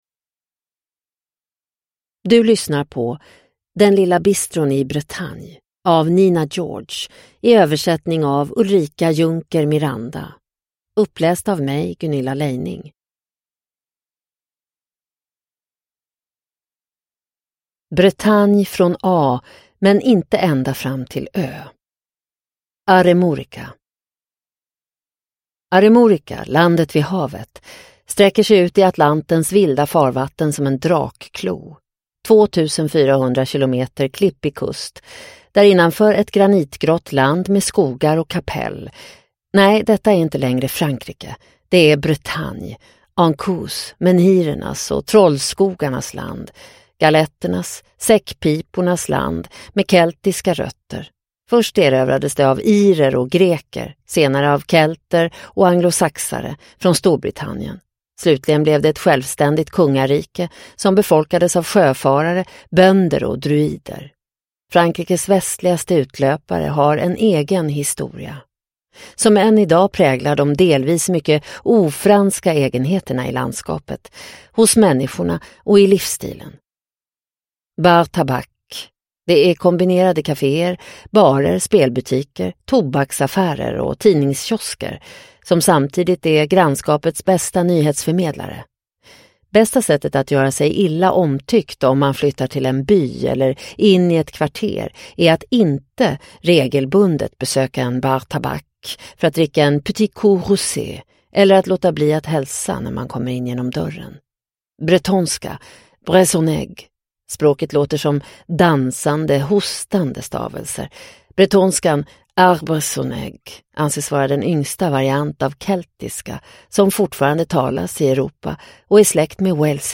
Den lilla bistron i Bretagne – Ljudbok – Laddas ner